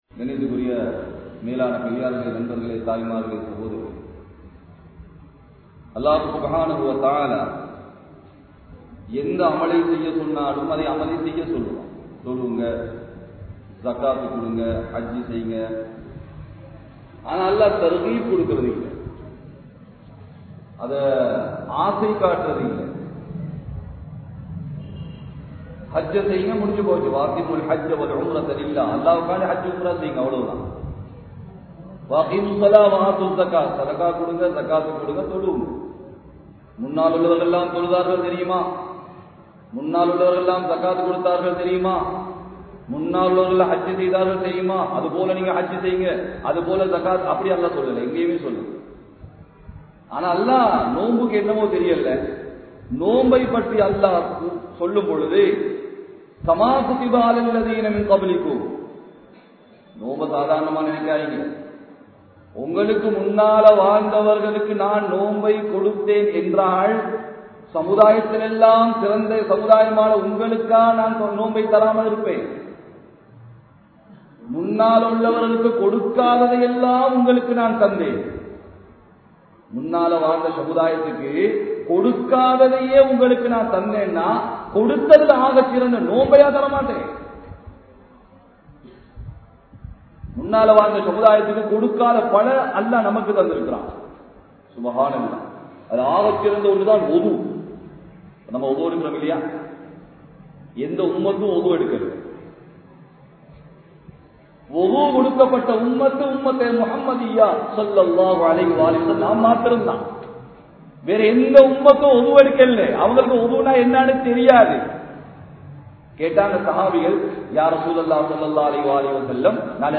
Ramalanum Thaqwavum (ரமழானும் தக்வாவும்) | Audio Bayans | All Ceylon Muslim Youth Community | Addalaichenai